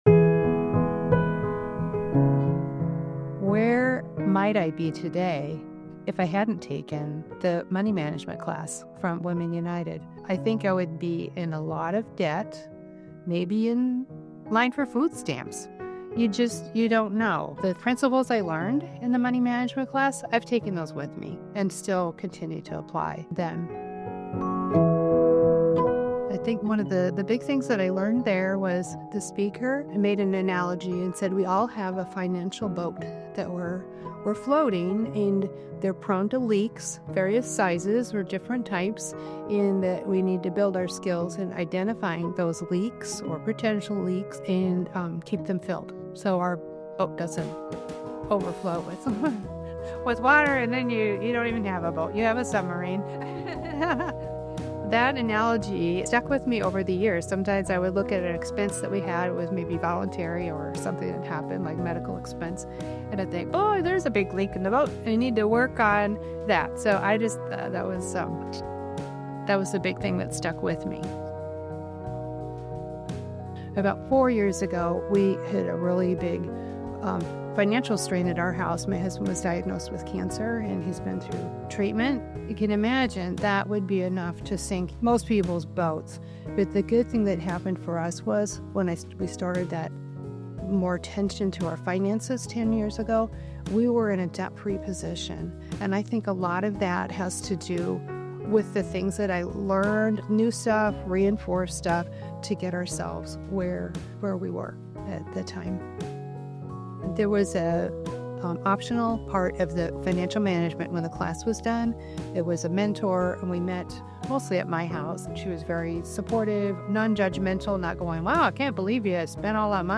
Click here to hear one woman's story.
WU Testimonial_2025.mp3